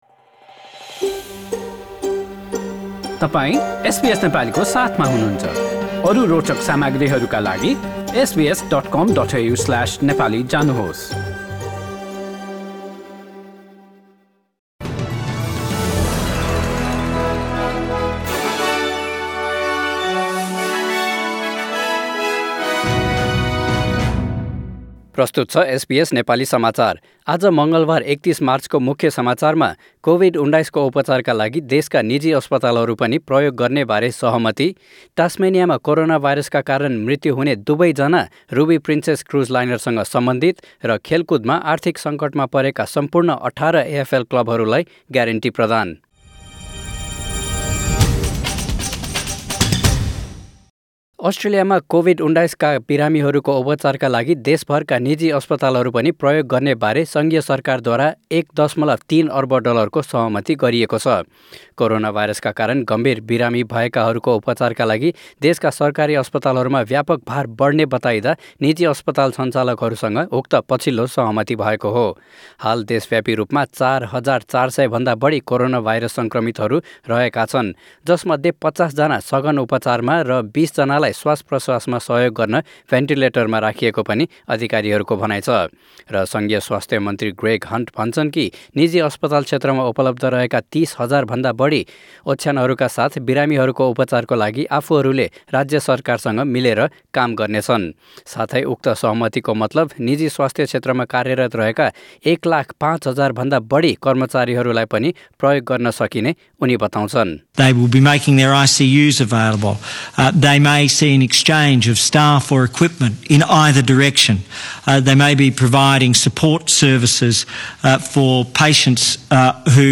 Listen to the latest news headlines in Australia from SBS Nepali radio